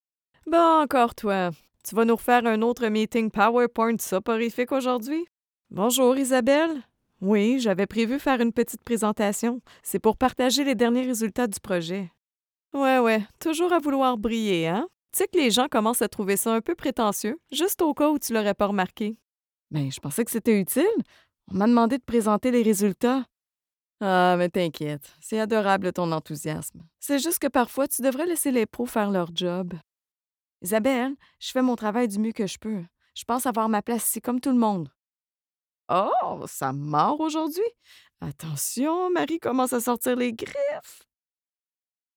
Female
French (Canadian)
Yng Adult (18-29), Adult (30-50)
Explainer Videos
0529De_la_conversation.mp3